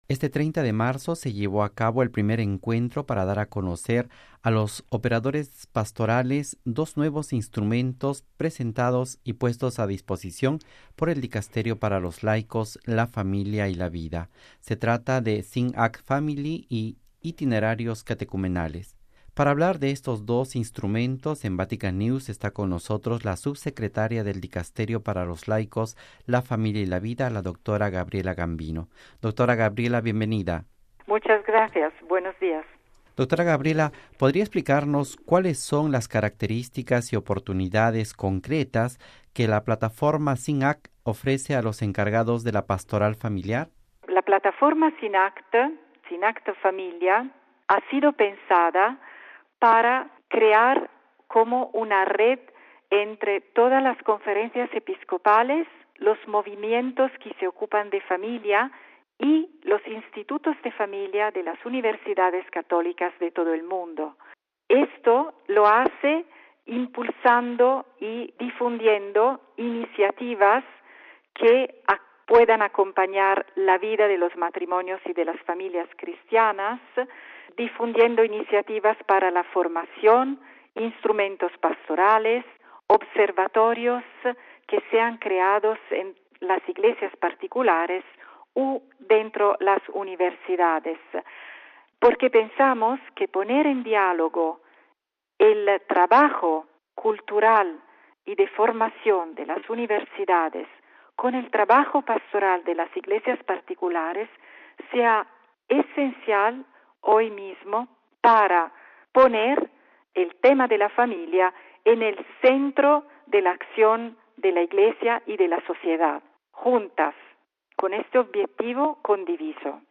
En la entrevista concedida a Vatican News/Radio Vaticana, Gabriella Gambino habla de las oportunidades de la plataforma SynAct Family y del nuevo documento, delineando las nuevas líneas de acción que la Iglesia está invitada a emprender para acompañar más eficazmente a las familias, a los jóvenes y a los ancianos en el descubrimiento de su vocación específica.